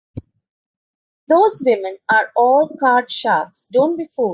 Pronunciado como (IPA)
/fuːld/